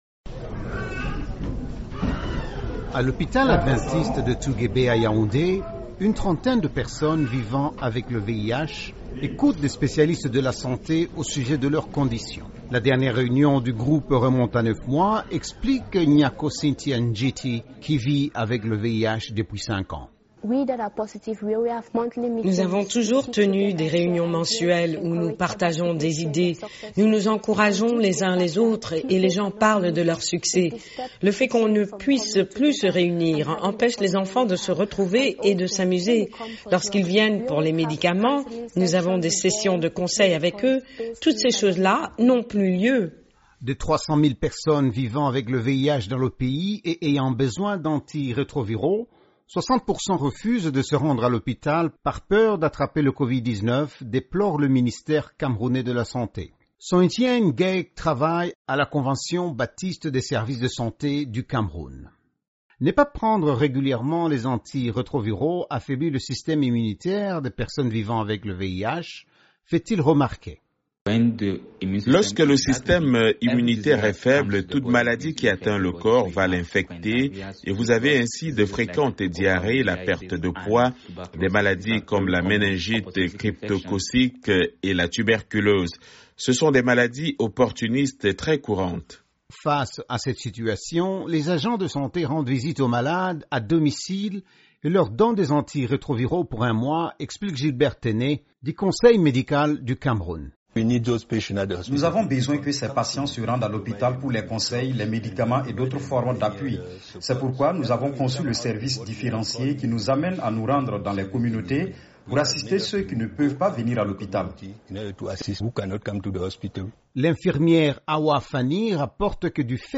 Des dizaines de milliers de personnes vivant avec le VIH au Cameroun refusent de se rendre à l’hôpital par crainte du nouveau coronavirus. Ils se mettent à risque s’ils ne prennent leurs antirétroviraux, avertissent les agents de santé. Reportage